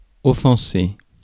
Ääntäminen
IPA: [ɔ.fɑ̃.se]